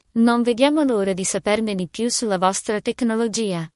Custom AI voices generated in Italian.
AI Voice in Italian
italian-tts.mp3